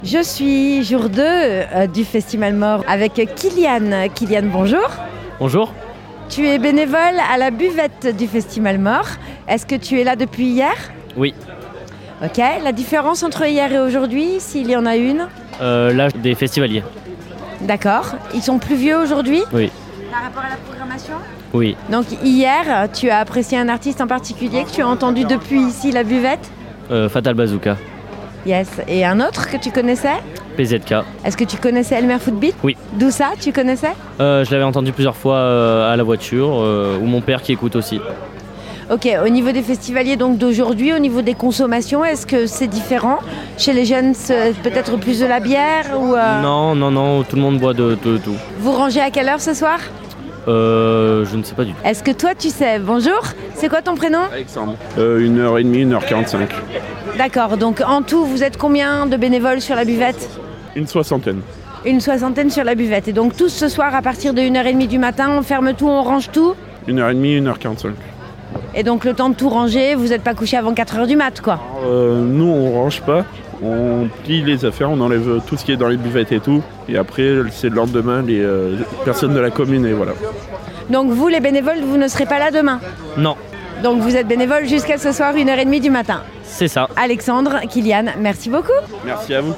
Nous parlons ensemble de joie , de fête, de bonne humeur et de sourires mais aussi de chiffres et bien sûr de musique 😉 Interviews Festi'malemort email Rate it 1 2 3 4 5